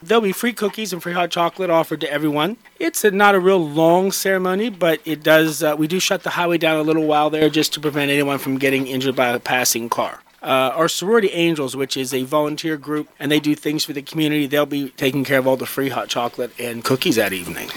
Wednesday, December 11 is the date set for the town’s Christmas Tree lighting.  Mayor Jack Coburn shared details with WCBC about the event that begins at 6pm…